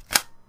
shotgun_put_ammo-2.wav